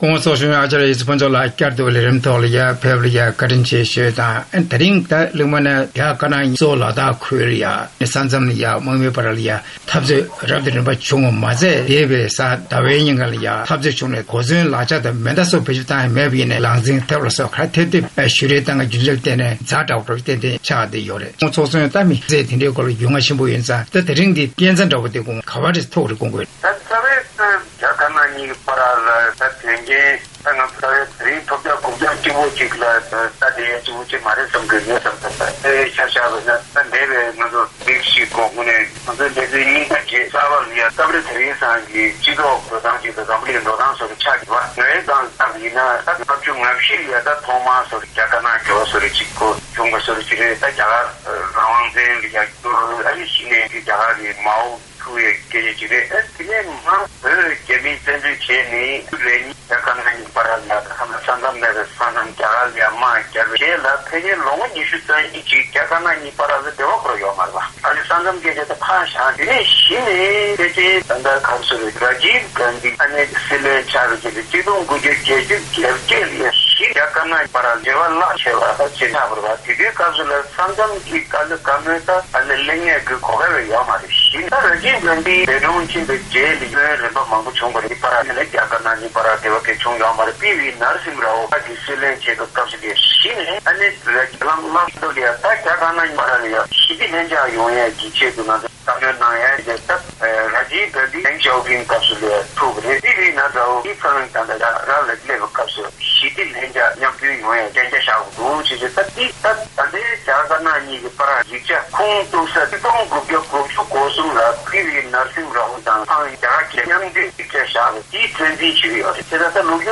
གླེང་མོལ་ཞུས་པར་གསན་རོགས།།